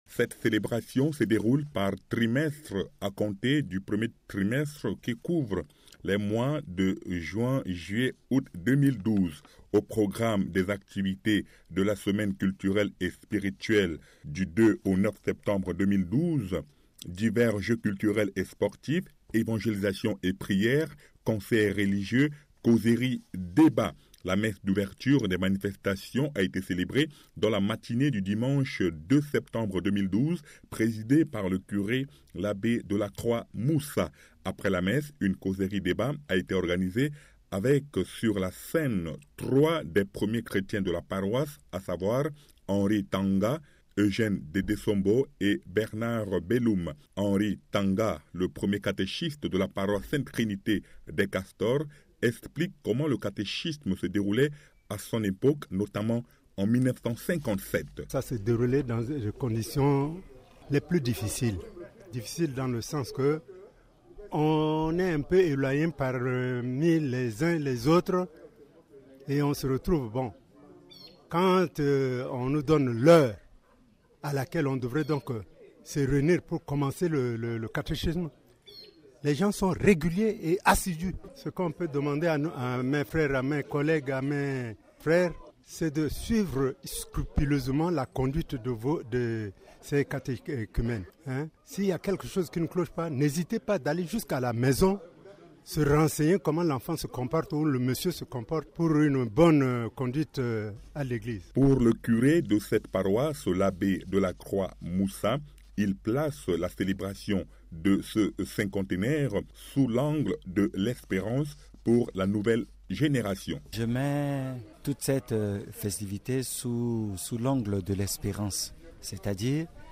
La paroisse de la Sainte Trinité des Castors a débuté, à Bangui, dimanche 2 septembre, les manifestations de ce jubilé qui vont s'étendre sur un an des détails avec notre correspondant local